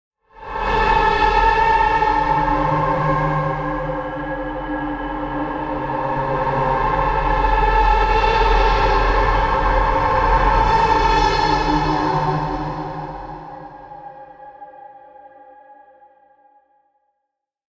На этой странице собраны звуки души — необычные аудиокомпозиции, отражающие тонкие эмоциональные состояния.
Звук встревоженной души